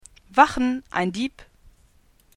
Deutsche Sprecher (f)
Selbsteinsch�tzung: sp�ttisch